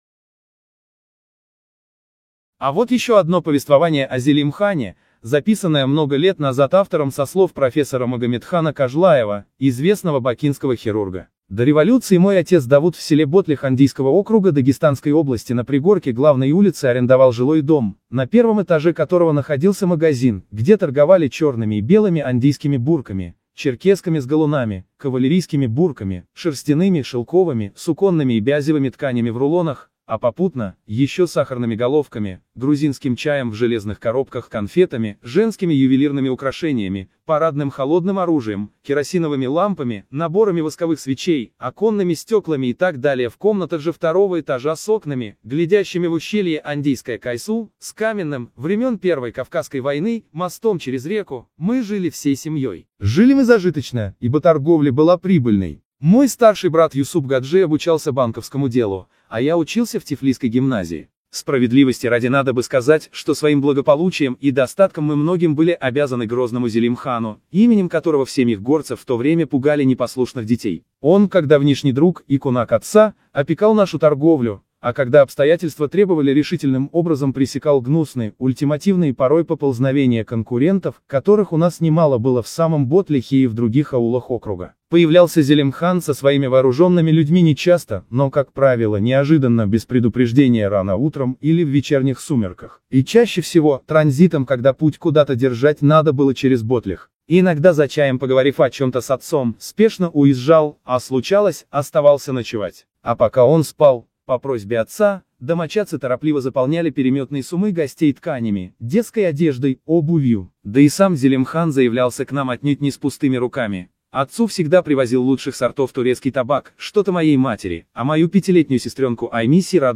аудиорассказ